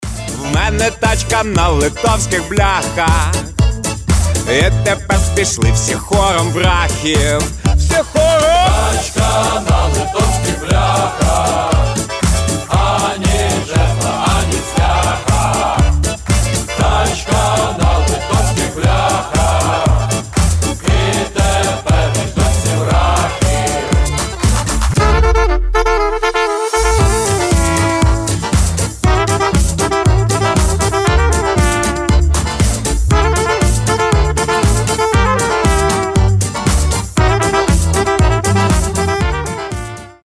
• Качество: 320, Stereo
забавные
веселые
инструментальные
цыганские
хор